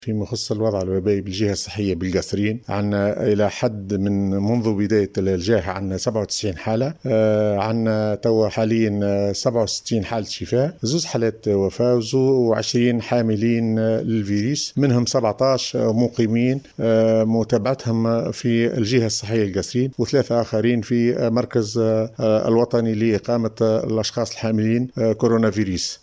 أكد  المدير الجهوي للصحة بالقصرين عبد الغني الشعباني في تصريح إعلامي أن  الوحدات الصحية ستتخذ استراتجية جديدة لرفع العينات للأشخاص المخالطين للمصابين بكوفيد 19 دون انتظار ظهور الأعراض و خاصة أعوان و إطارات  الصحة باعتبارهم  الحلقة الأولى في سلسلة مقاومة وباء كورونا.